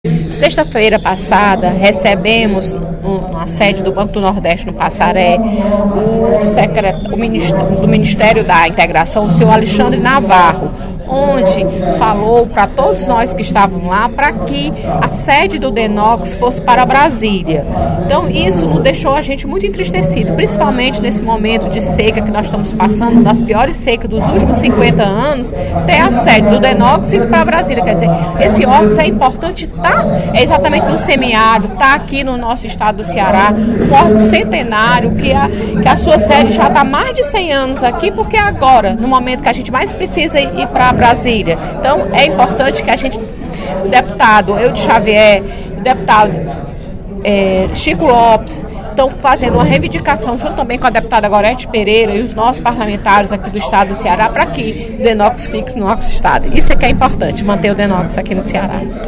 A deputada Fernanda Pessoa (PR) defendeu, nesta terça-feira (16/04), durante o primeiro expediente, a permanência no Estado da sede do Departamento Nacional de Obras Contra as Secas (Dnocs), “um órgão centenário, que já fez e faz muito pelo nosso Ceará”.